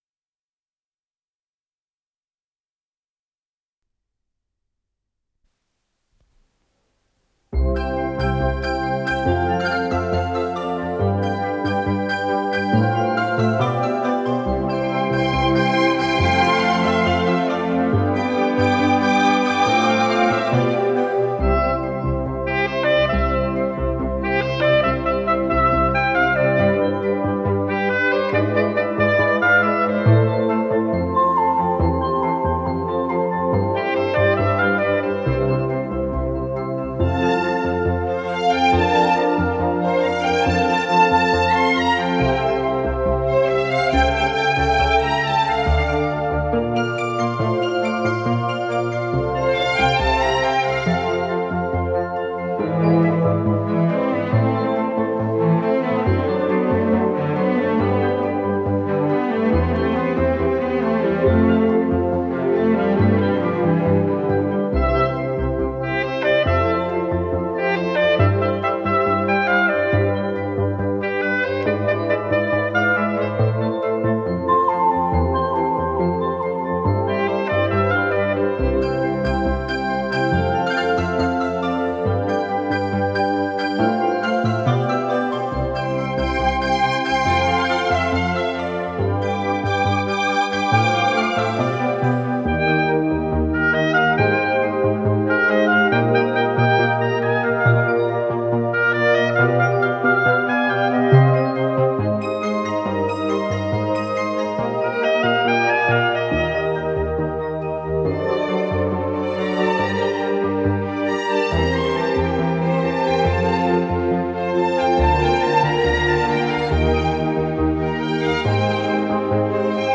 Скорость ленты38 см/с
Тип лентыСвема Тип А4620-6Р
МагнитофонМЭЗ-109А